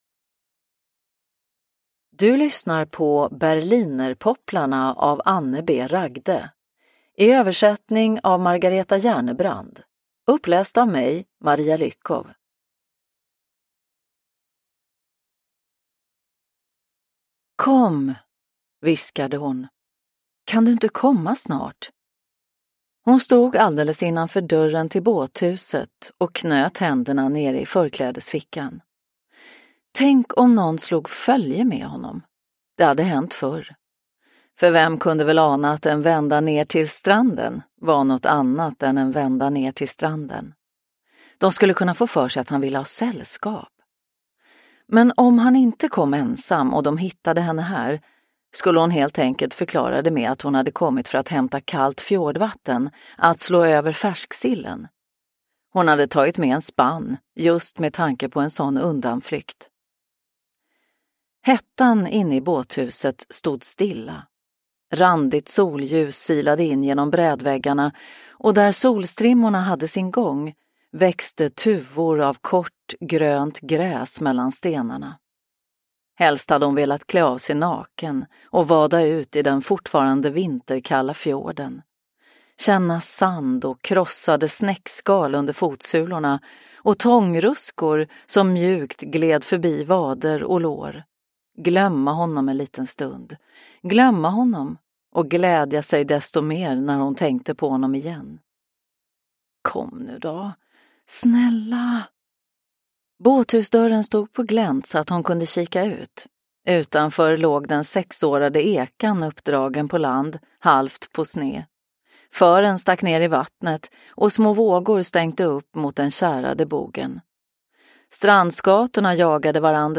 Berlinerpopplarna – Ljudbok – Laddas ner